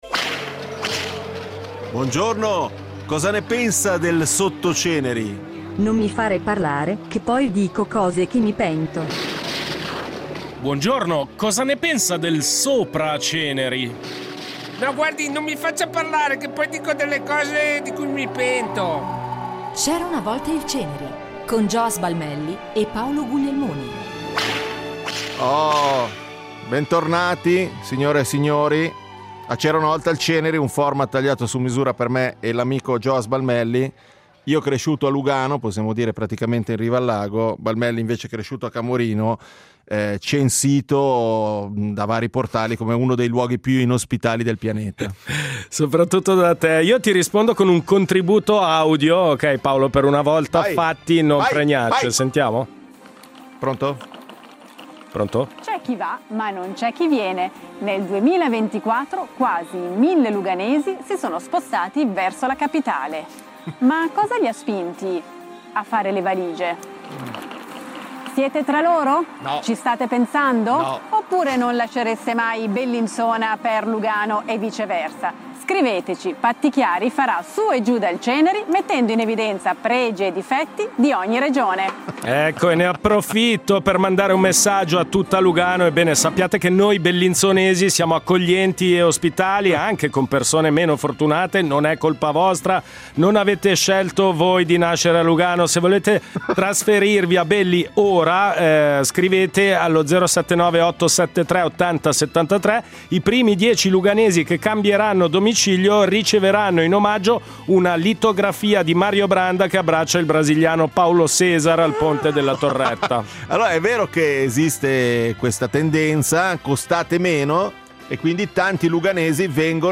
In studio una coppia